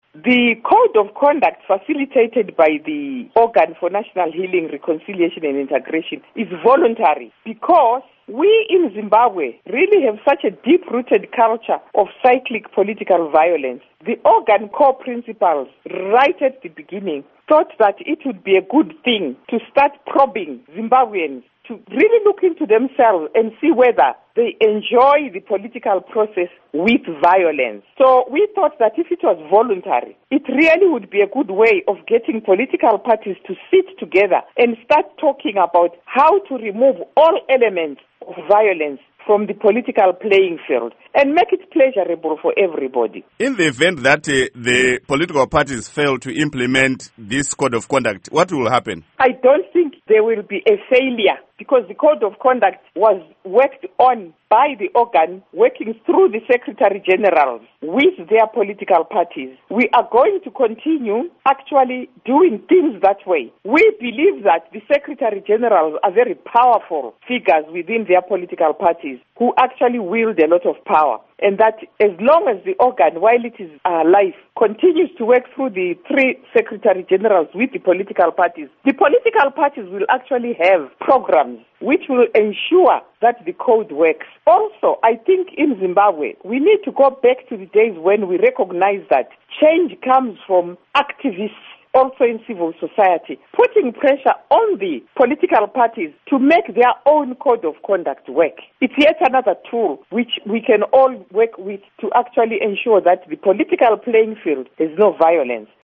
Interview With Sekai Holland